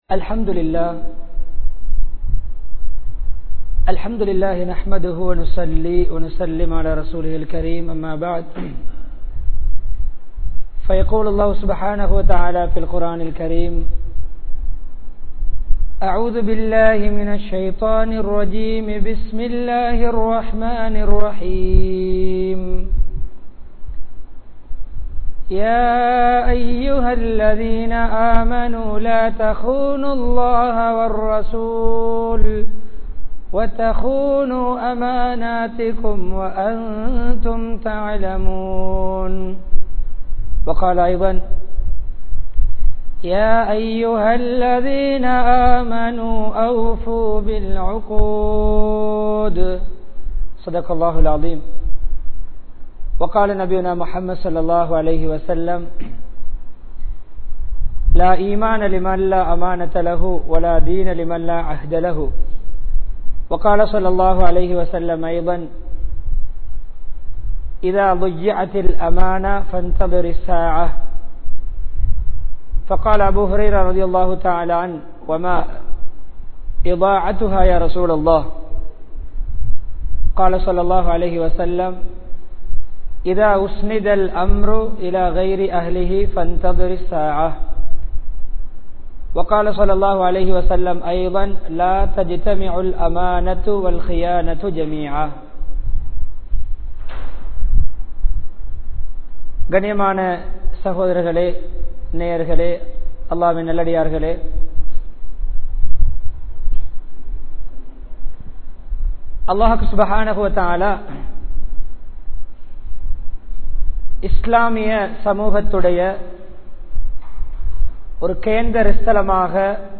Masjidh Niruvaahihalum Amaanithangalum (மஸ்ஜித் நிருவாகிகளும் அமானிதங்களும்) | Audio Bayans | All Ceylon Muslim Youth Community | Addalaichenai